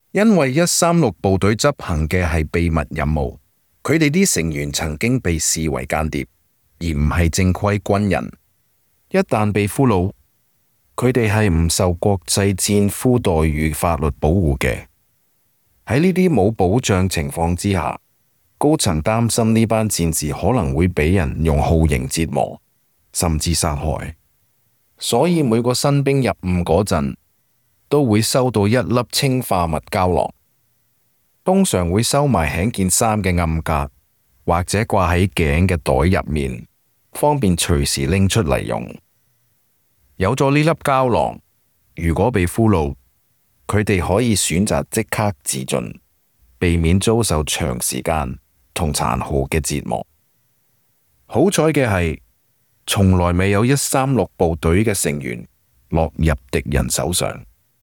Special Operations Voiceovers
2_CANTO_Cyanide_Pill_-_Cantonese_Voiceover__eq_.mp3